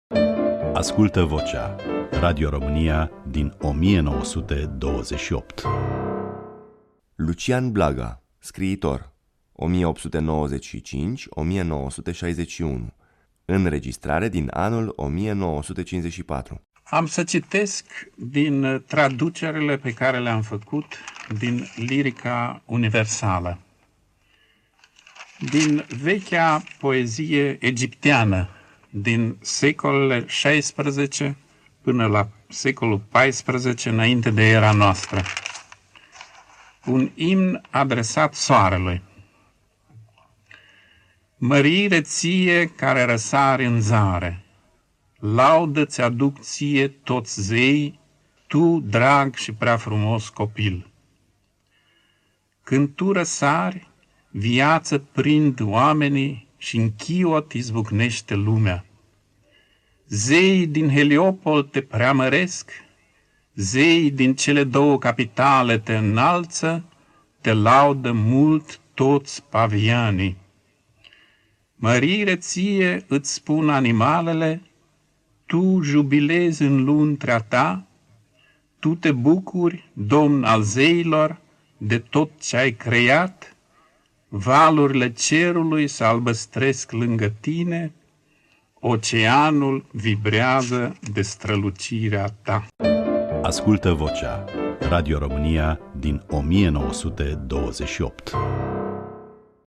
Asculta-Vocea-lui-Lucian-Blaga-la-Radio-Romania-Cultural.mp3